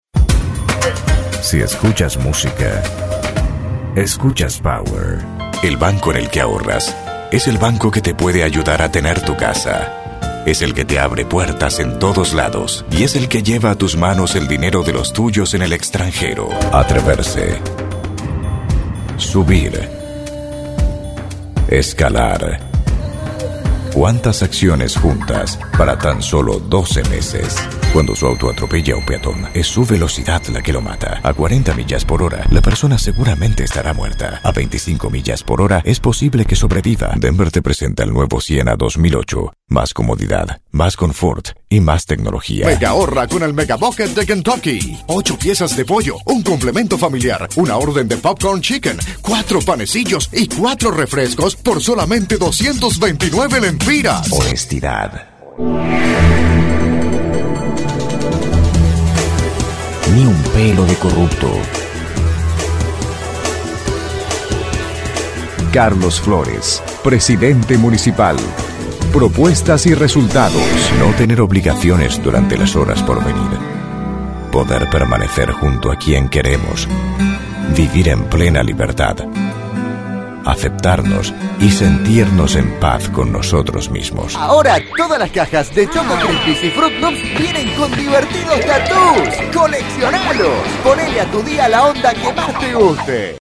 Warm and deep voice for narrations, documentaries, trailers, commercials and promos.
Sprecher für castellanisch, spanisch, portugiesisch und französisch
Sprechprobe: Industrie (Muttersprache):